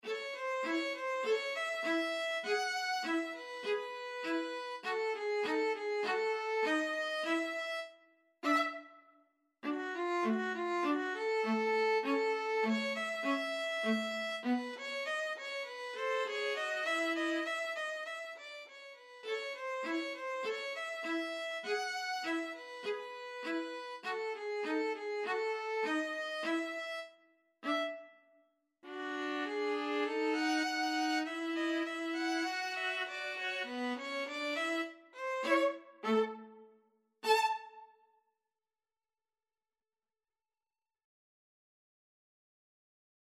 A light-hearted Ragtime-style piece.
4/4 (View more 4/4 Music)
Violin-Viola Duet  (View more Easy Violin-Viola Duet Music)
Jazz (View more Jazz Violin-Viola Duet Music)